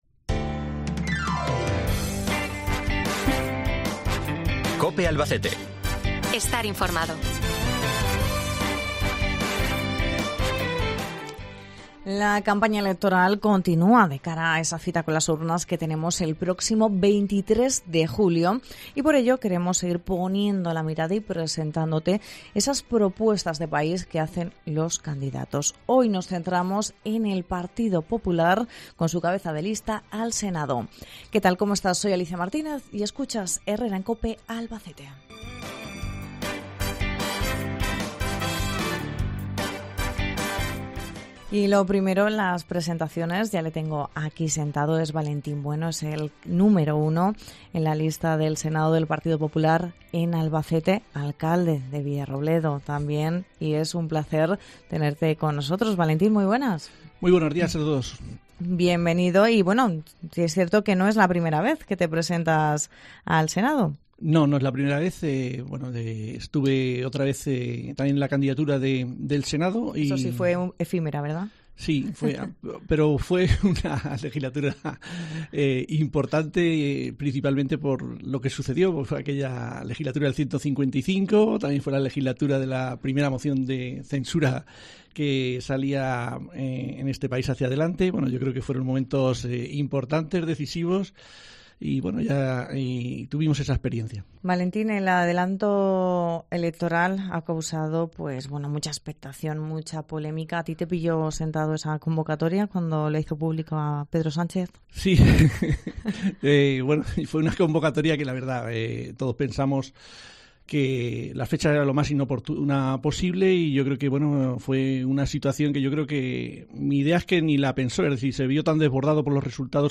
Hoy hablamos con Valentín Bueno, el número uno al Senado por el PP de Albacete y actual alcalde de Albacete de Villarrobledo.